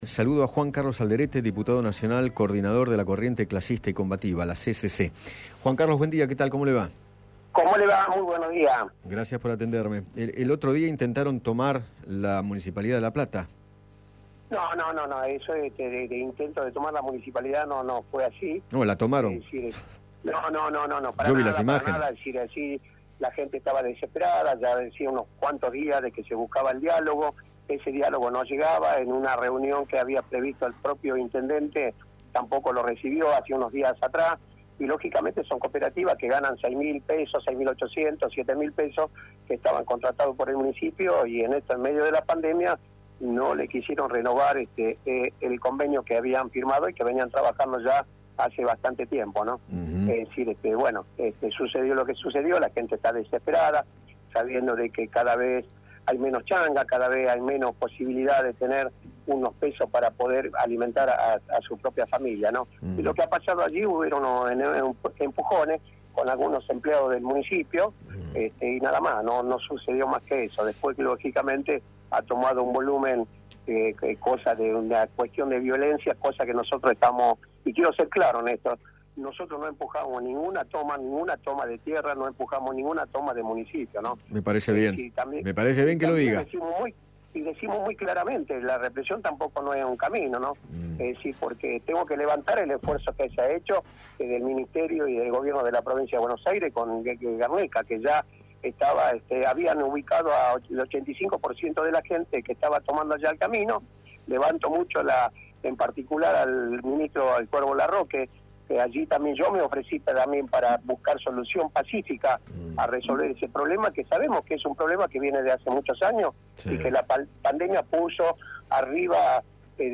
Juan Carlos Alderete, Diputado Nacional y dirigente de la Corriente Clasista y Combativa, dialogó con Eduardo Feinmann sobre el aumento de ocupaciones de terrenos privados en el país y se refirió al tenso momento que se vivió este miércoles en la Municipalidad de La Plata.